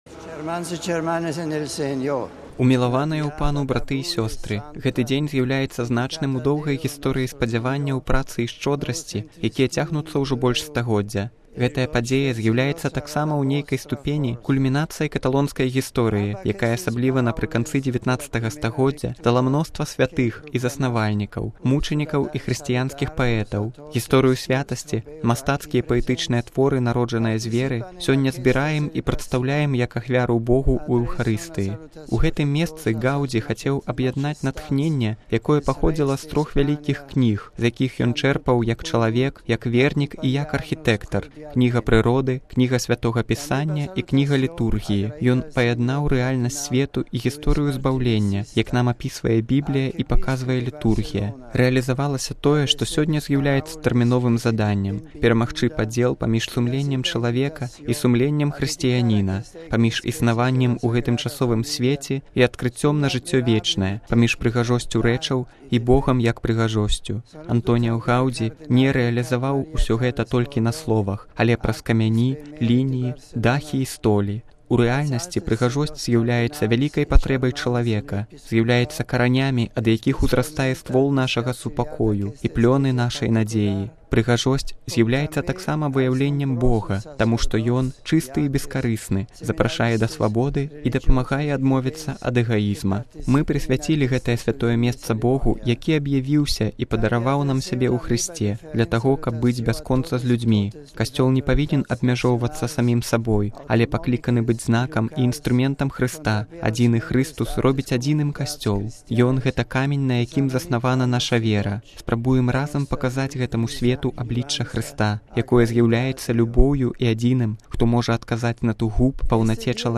Падчас Эўхарыстыі Святы Айцец звярнуў сваю гамілію да сабраных.